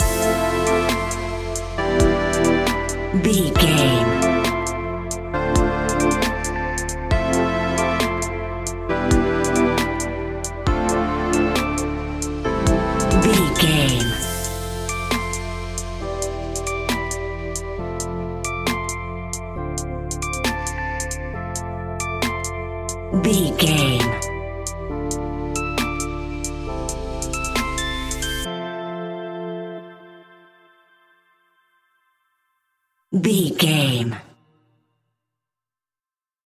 Aeolian/Minor
hip hop
laid back
groove
hip hop drums
hip hop synths
piano
hip hop pads